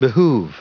Prononciation du mot behoove en anglais (fichier audio)
Prononciation du mot : behoove